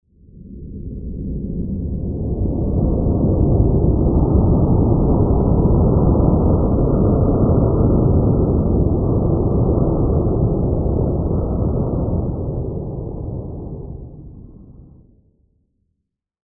Громовые раскаты, завывание ветра и шум ливня создают эффект присутствия.
Звук космического шторма в просторах вселенной